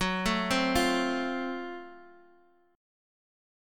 F/F# chord
F-Major-Fsharp-x,x,4,2,1,1-8.m4a